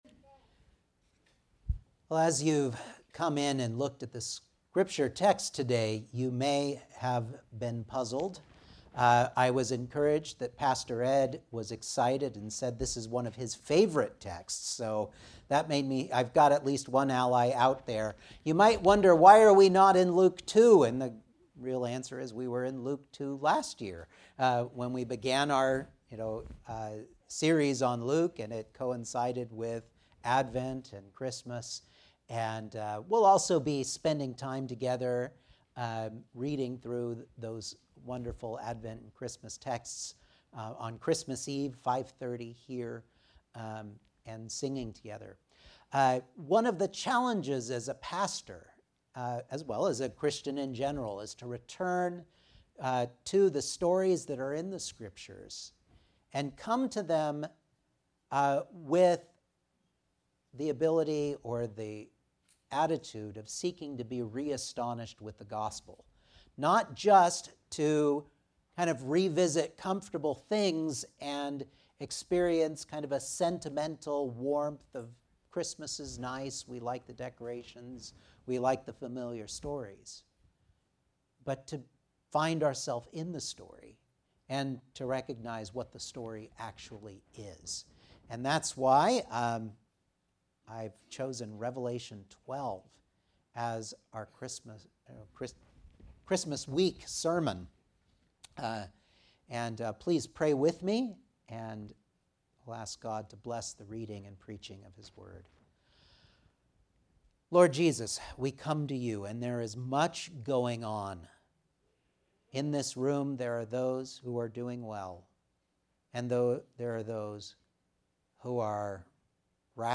Revelation 12 Service Type: Sunday Morning Outline